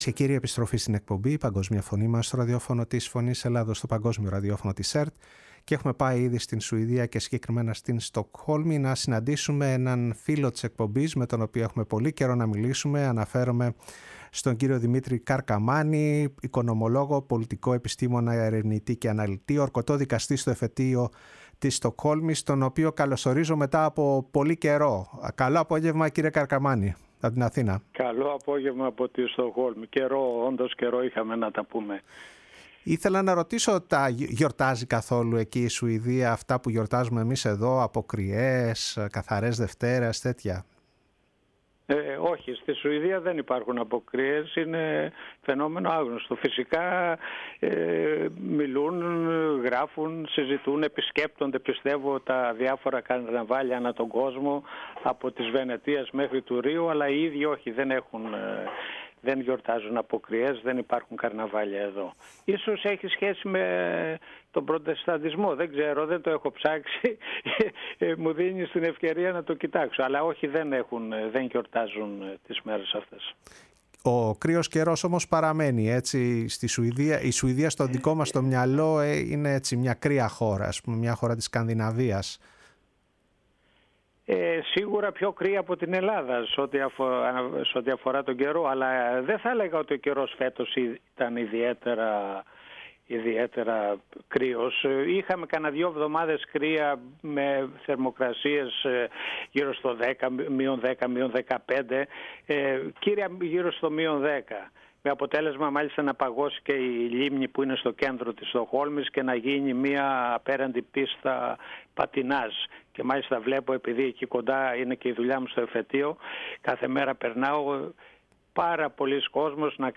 ΣΥΝΕΝΤΕΥΞΕΙΣ Συνεντεύξεις